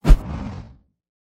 Hit_fly (1)-ytanmo632j.mp3